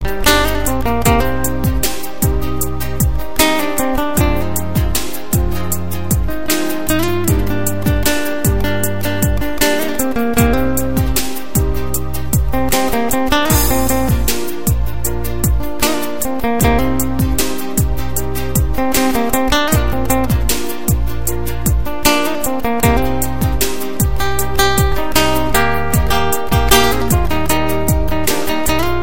• Качество: 128, Stereo
спокойные
без слов
арабские
акустическая гитара
Красивая игра на гитаре